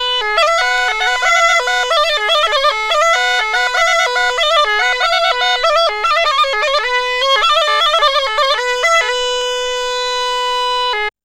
AFGANPIPE1-R.wav